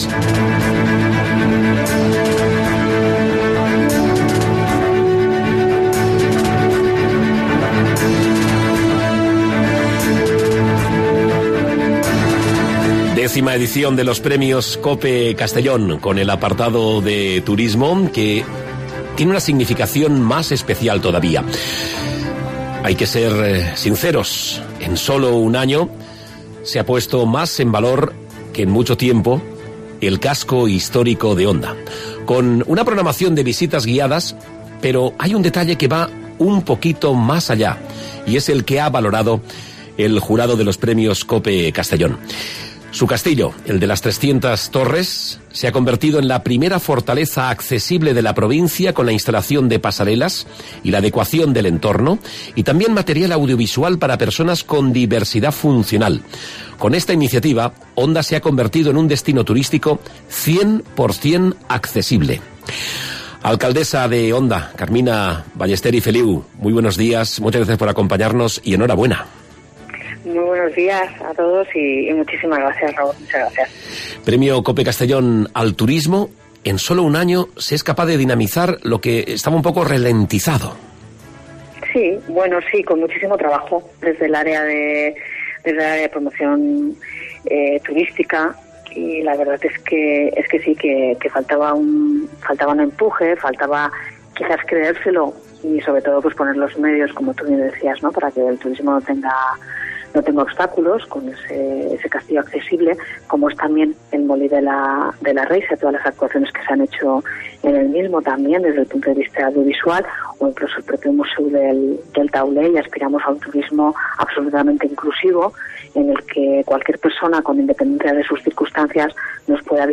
Entrevista a Carmina Ballester (Onda)